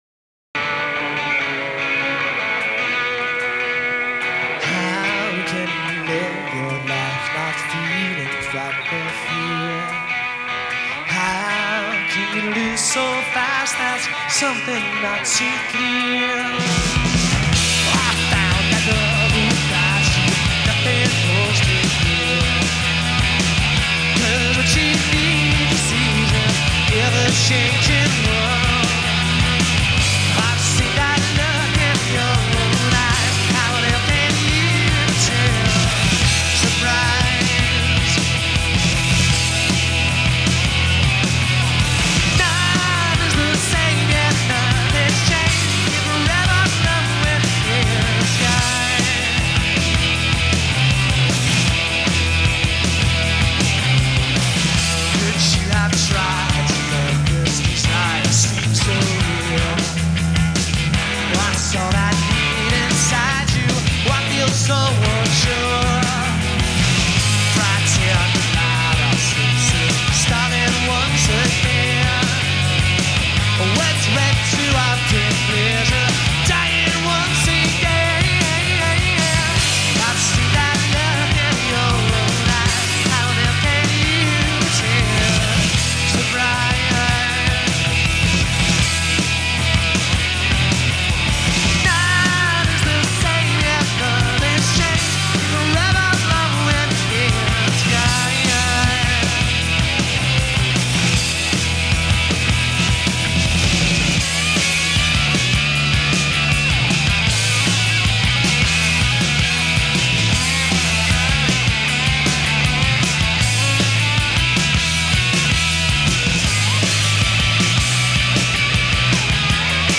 enregistrée le 08/06/1993  au Studio 105